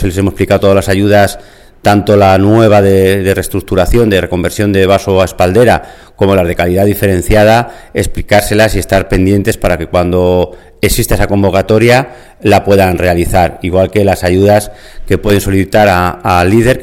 El director provincial de Agricultura, Medio Ambiente y Desarrollo Rural, Santos López, habla de las posibles ayudas a disposición de la DO vinos de Móndejar.